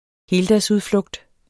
Udtale [ ˈheːldas- ]